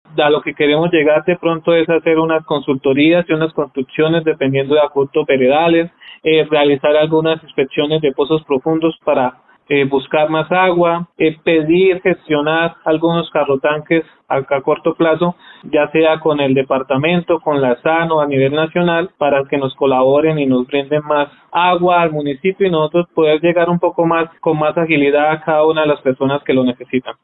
Javier Ortiz Atuesta, secretario de Planeación y Obras Públicas de Los Santos.